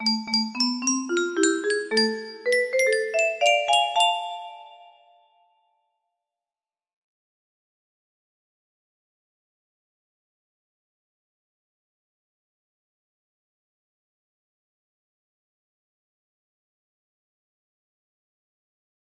midnight hamster shadow music box melody